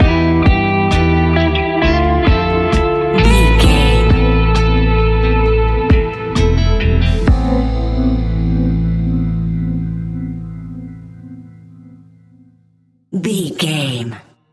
Ionian/Major
A♭
sparse
new age
chilled electronica
ambient